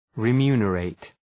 Προφορά
{ri:’mju:nə,reıt} (Ρήμα) ● ανταμείβω ● αποζημιώνω